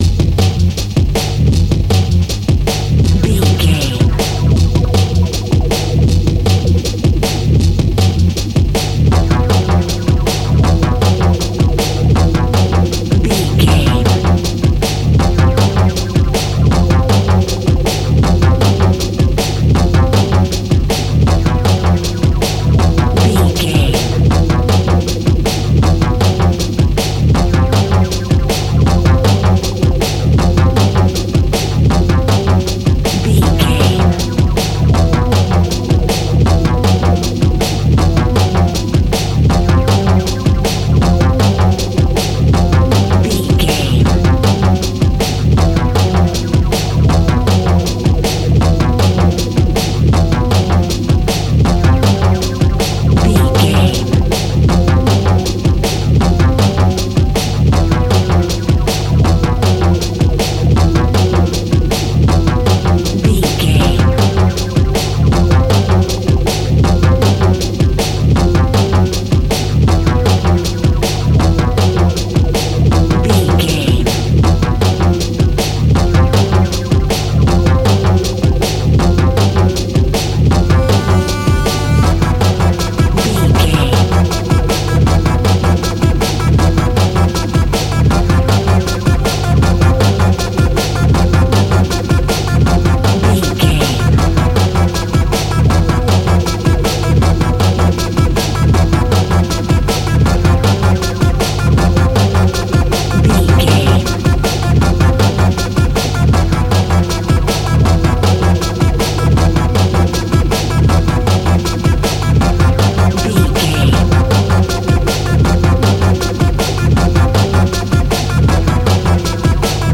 Atonal
Fast
frantic
energetic
dark
hypnotic
industrial
drums
synthesiser
Drum and bass
electronic
instrumentals
synth bass
synth lead
synth pad